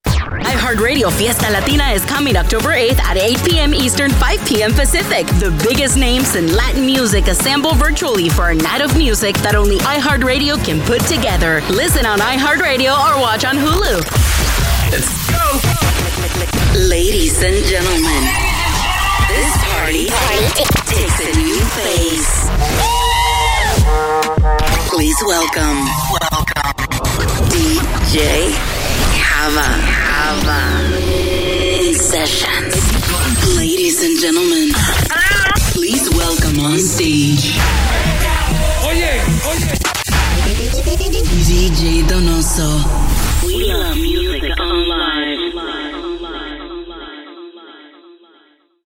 Her sound is bold yet approachable perfect for CHR, Hot AC, Regional Mexican, Spanish Contemporary, Urban, and beyond.
Imaging English Demo